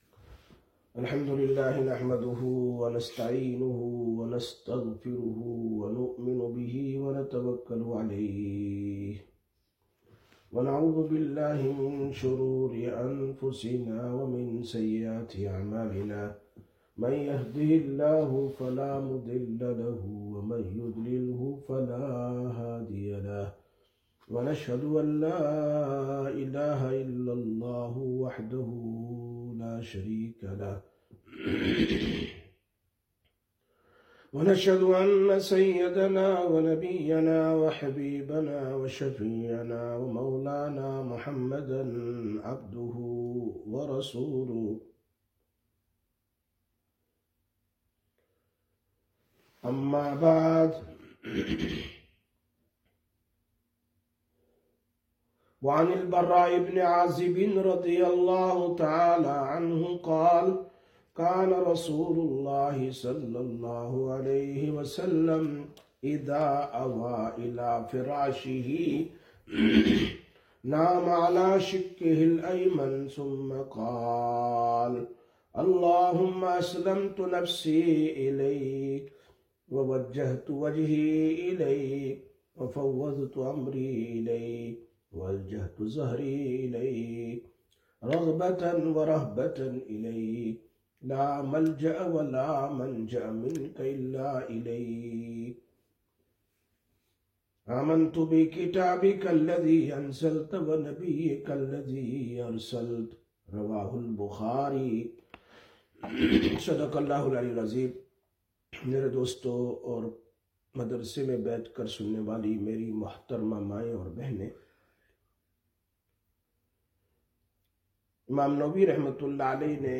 24/09/2025 Sisters Bayan, Masjid Quba